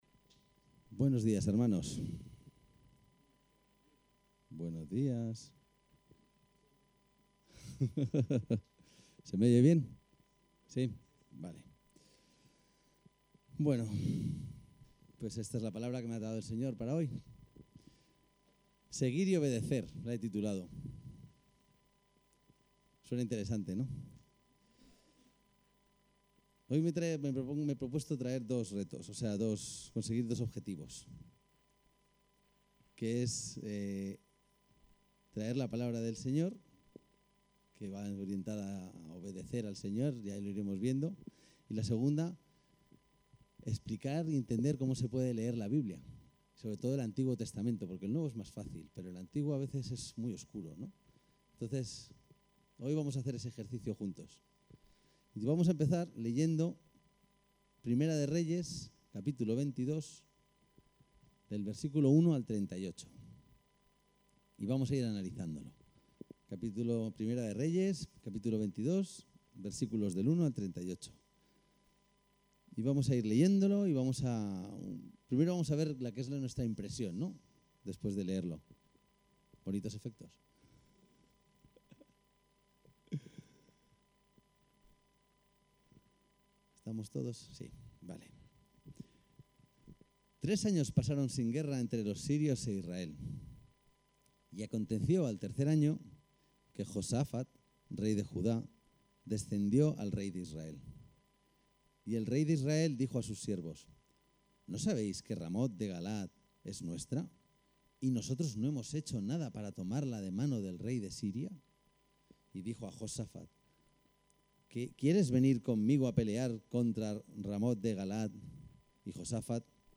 El texto de la predicación disponible aquí ==> seguir-y-obedecer